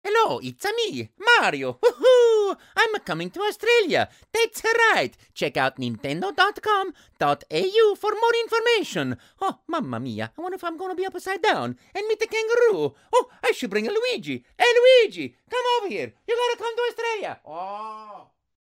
Voice clip promoting Charles Martinet's greeting events in AustraliaTranscript: